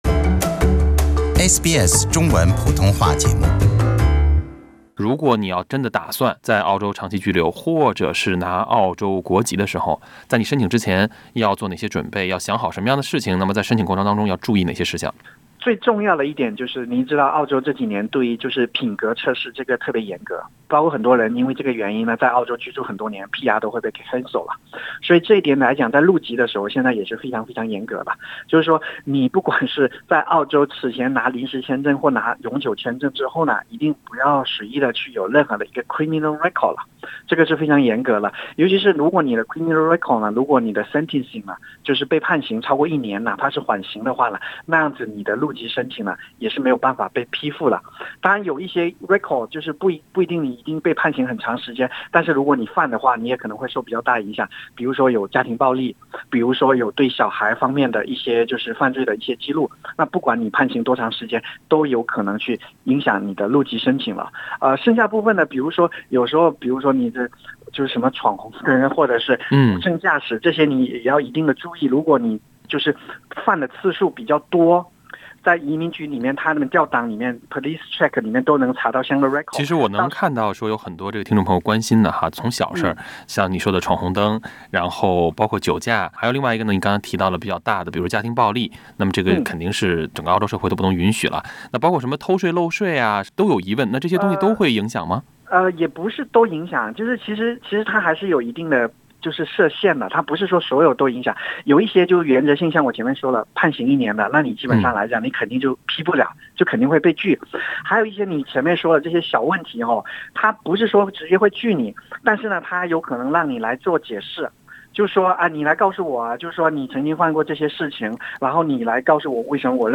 點擊收聽全部寀訪音頻 LISTEN TO 申请入籍澳洲的三大注意事项 SBS Chinese 06:58 cmn READ MORE 主动放弃、扎堆申请、政治因素……中国人入籍澳洲比例不高原因多样 品格測試 澳洲對於品格測試特彆嚴格。